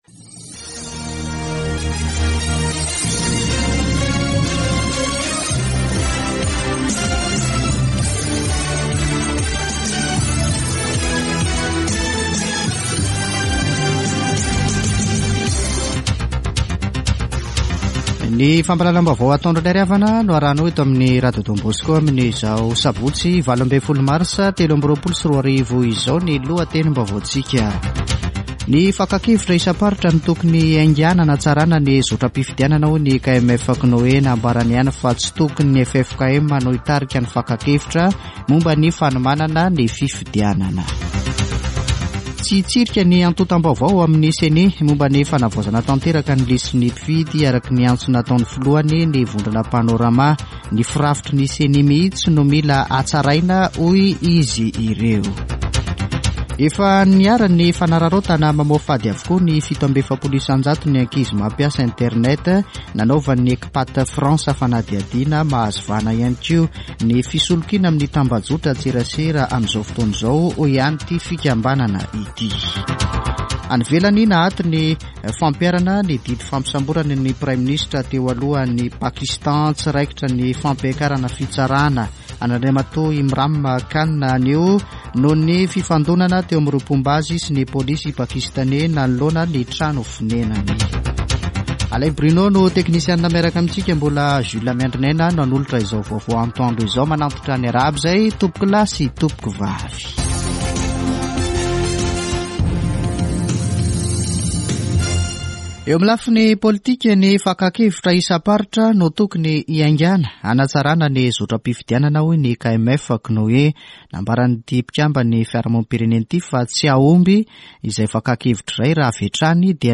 [Vaovao antoandro] Sabotsy 18 marsa 2023